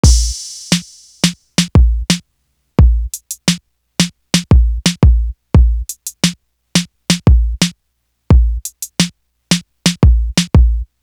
Lose Ya Life Drum.wav